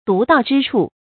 独到之处 dú dào zhī chù 成语解释 指与众不同的特殊的地方或见解。